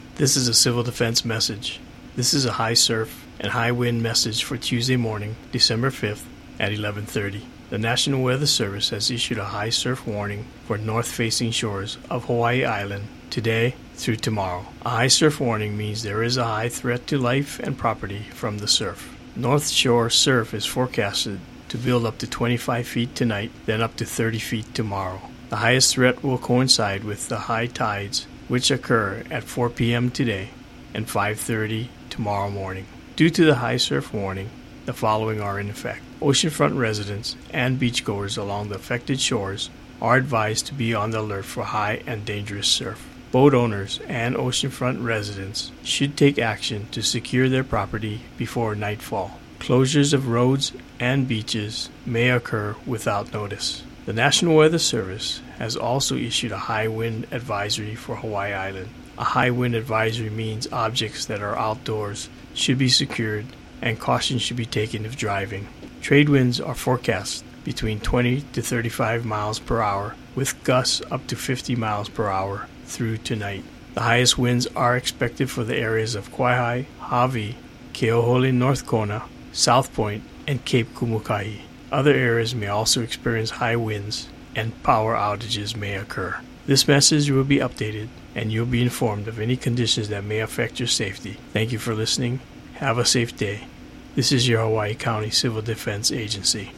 Hawaii County Civil Defense audio message